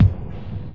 描述：这是我在2002年测试Tobybear的Deconstructor时做的声音套件的一部分，基本版本是一个简单的鼓循环，用移调、平移、颤音、延迟、混响、声码器进行切片和处理。和所有那些很酷的板载FX在这里和那里进行调整，原来的声音完全被破坏了。
标签： 切口 数字 鼓包 FX 毛刺 噪声 切片 SoundEffect中
声道立体声